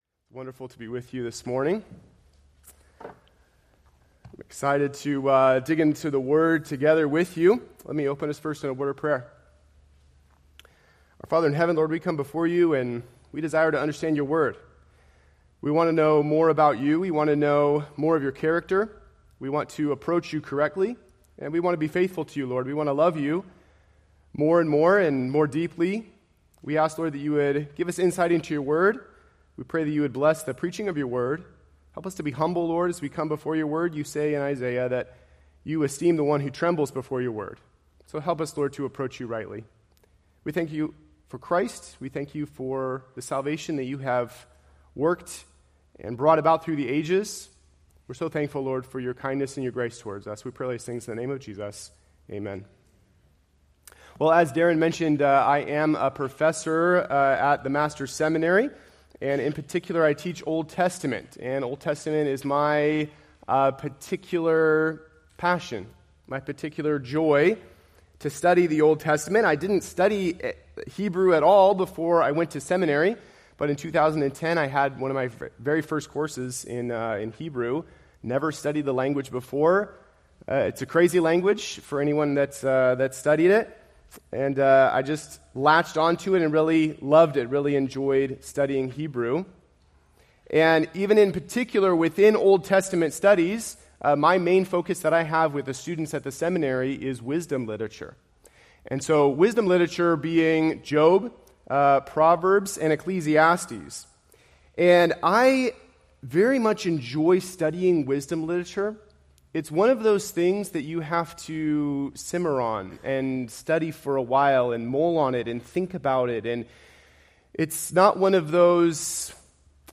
Preached July 28, 2024 from Proverbs 1:7